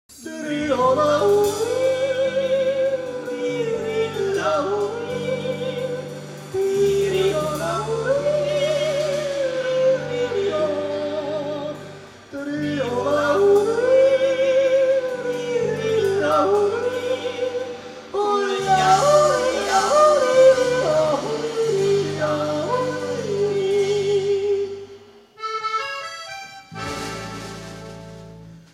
Konzert 2004 -Download-Bereich